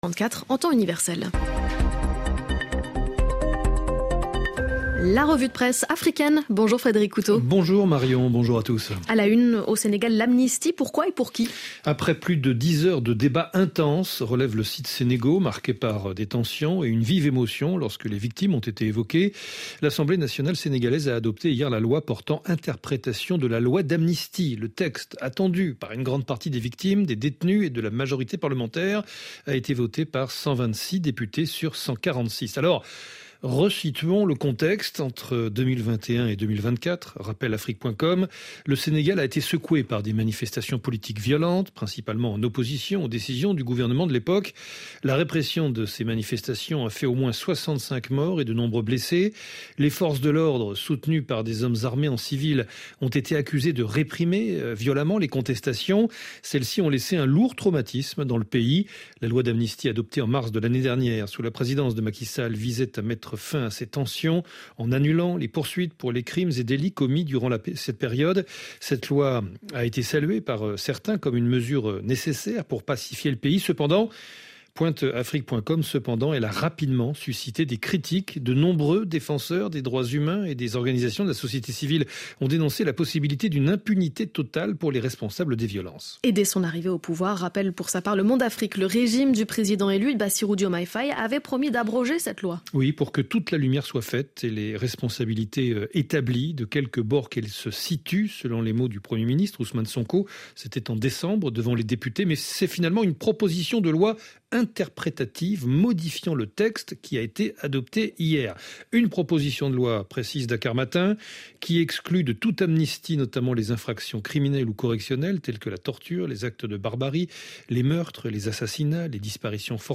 Tranche d'information afrique 03/04 12h30 GMT - 03.04.2025